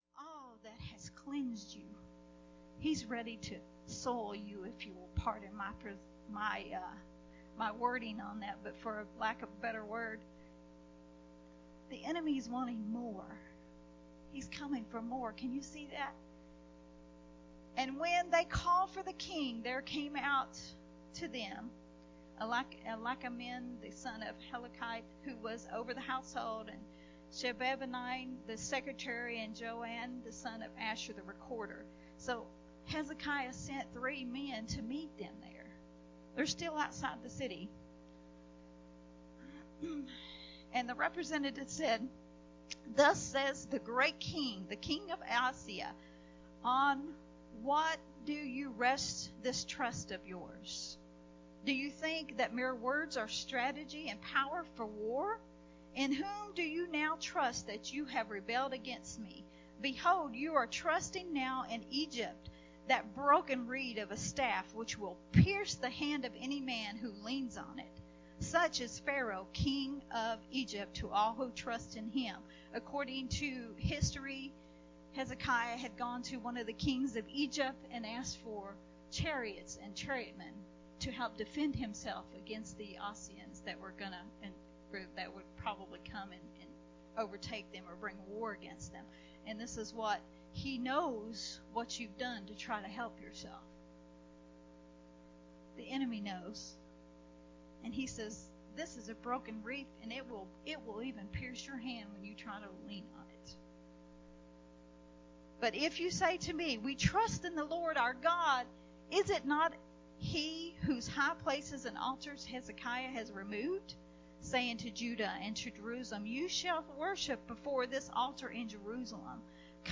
recorded at Unity Worship Center on 7/25/2021.